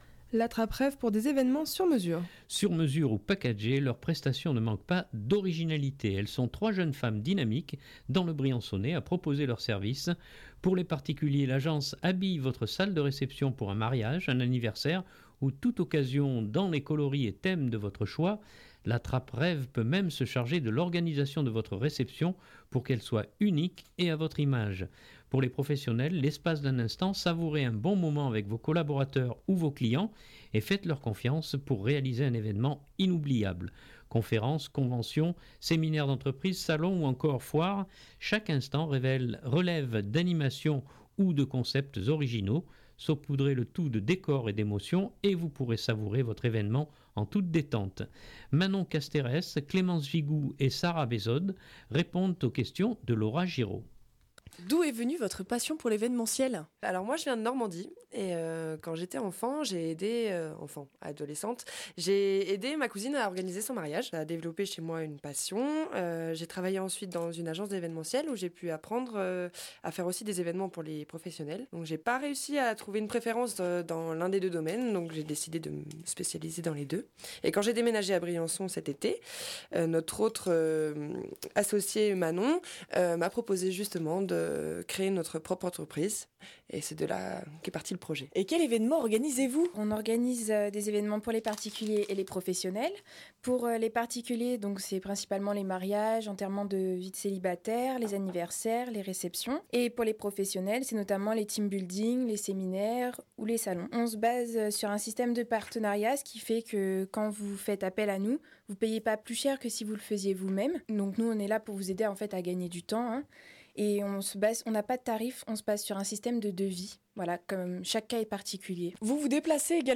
Sur mesure ou packagés, leurs prestations ne manquent pas d’originalité. Elles sont trois jeunes femmes dynamiques dans le Briançonnais à proposer leurs services.